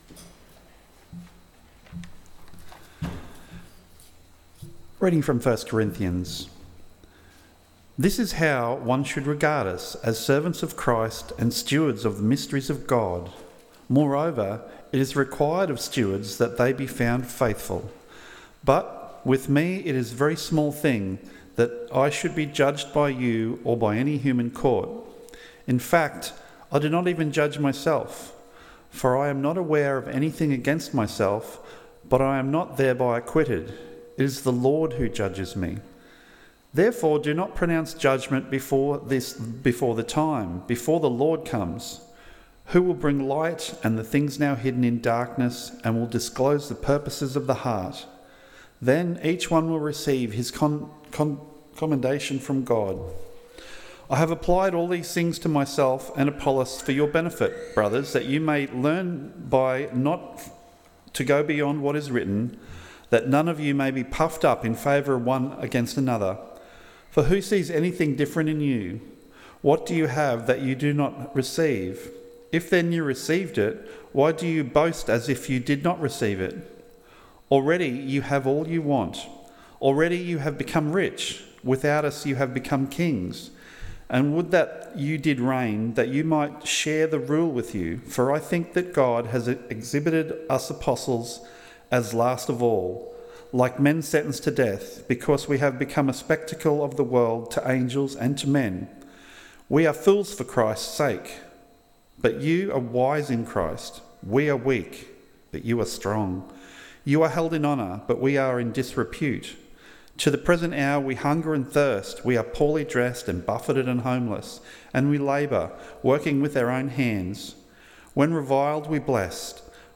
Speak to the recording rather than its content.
A Portrait Of Pastoral Ministry AM Service